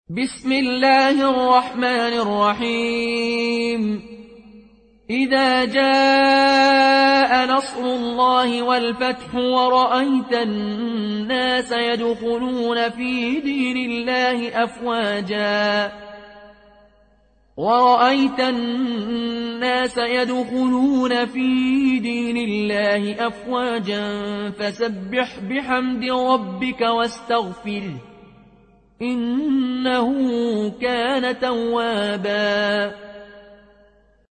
برواية قالون عن نافع